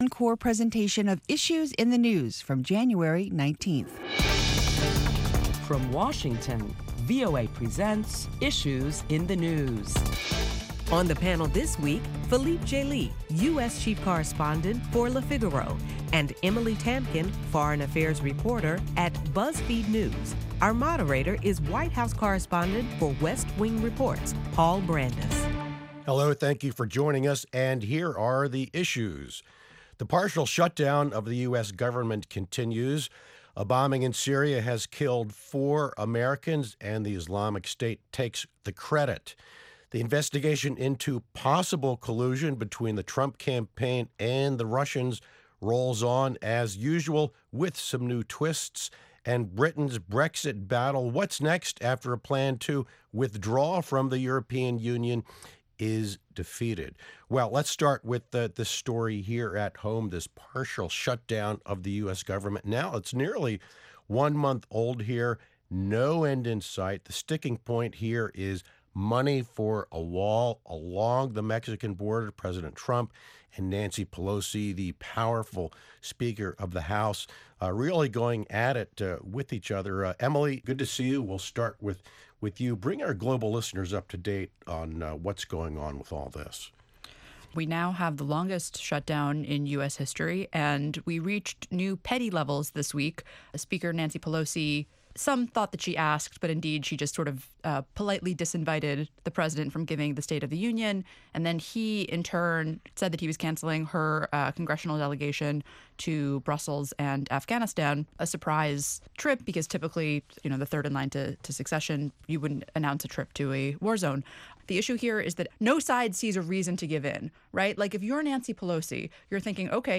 ***Due to the government shutdown, this program is a replay of last week's show (Jan. 19th). Listen to top Washington correspondents discuss the stalemate over the ongoing U.S. federal government shutdown and other top stories.